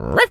dog_small_bark_04.wav